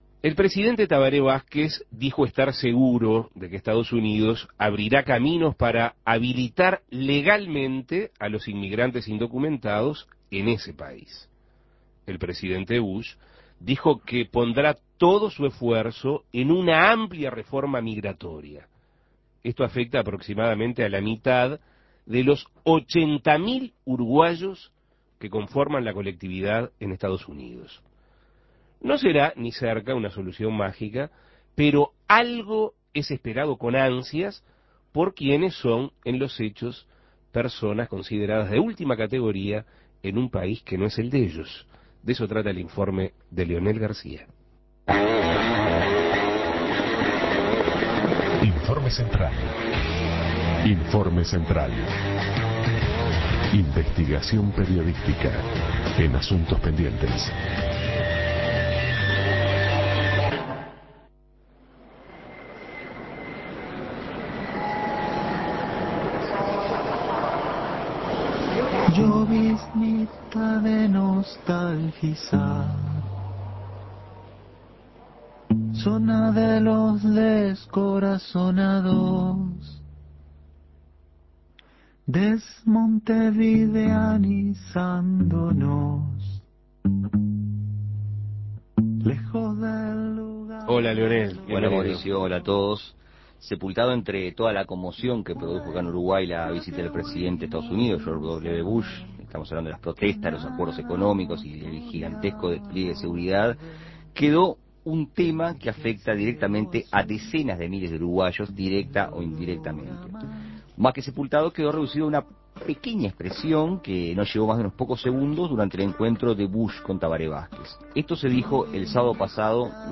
Escuche el informe de Asuntos Pendientes